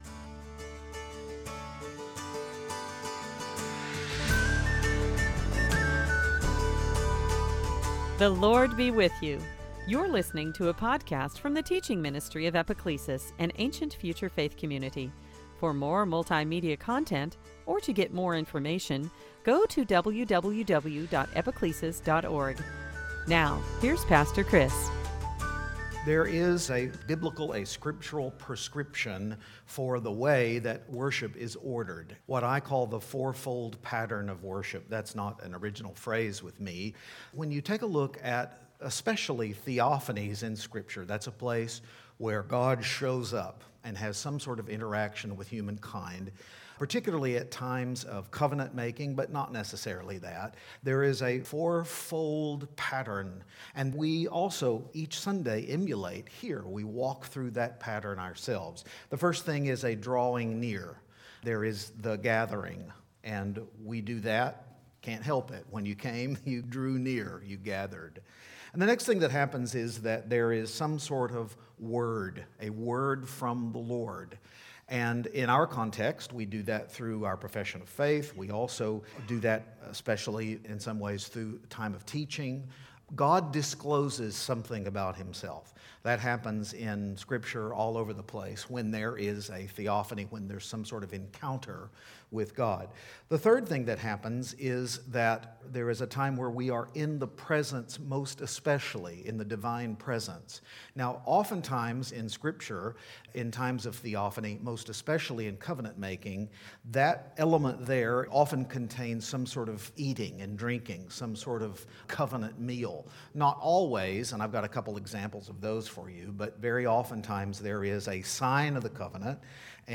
Matthew 28:1-10 Service Type: Easter Sunday Worship on that first resurrection morning?